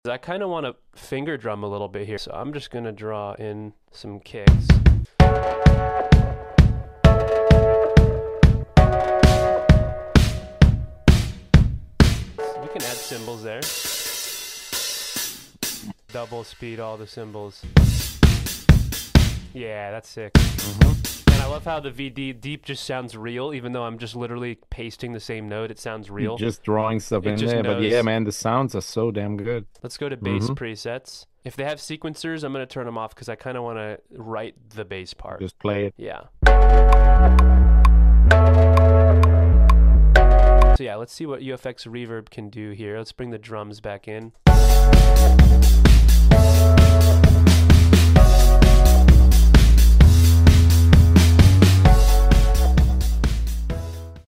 Virtual Drummer works really well for crafting your own drum arrangements with its one shots 🥁. Here's a short demonstration from our latest live beatmaking session.